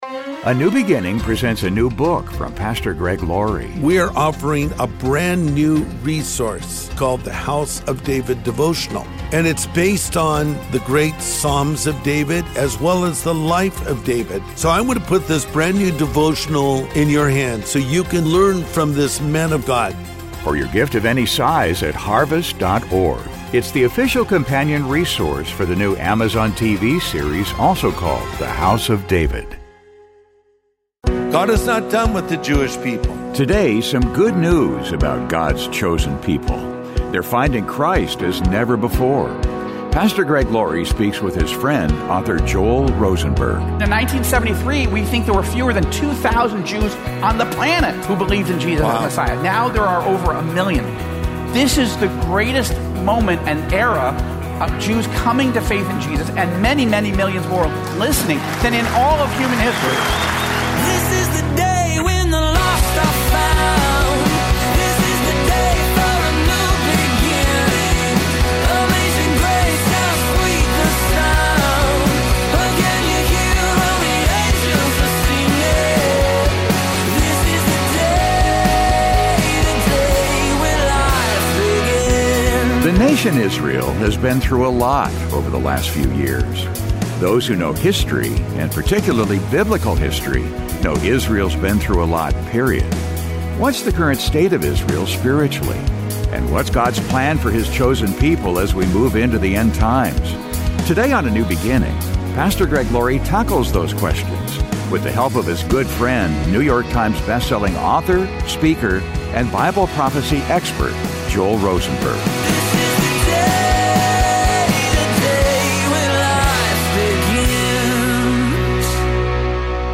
Today on A NEW BEGINNING, Pastor Greg Laurie tackles those questions with the help of his good friend, NY Times best-selling author, speaker and Bible prophecy expert, Joel Rosenberg.